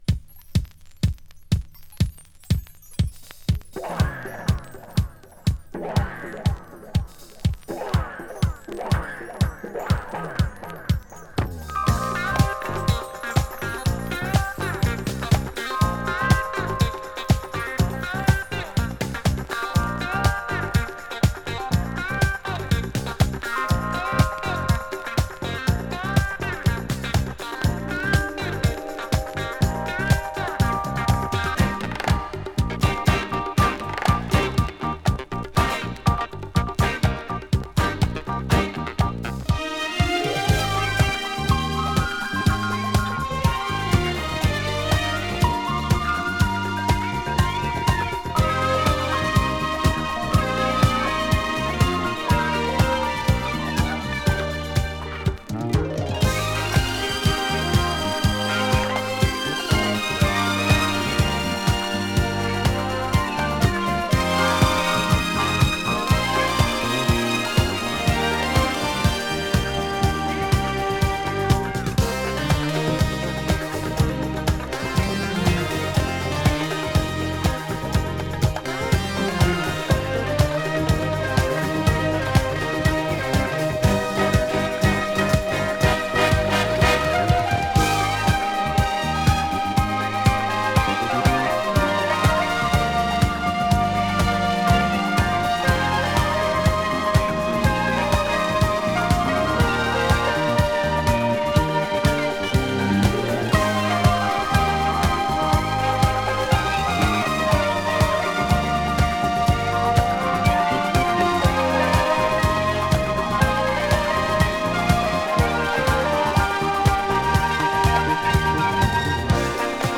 French Boogie!
【DISCO】
VG/VG スリキズによる僅かなチリノイズ sleeve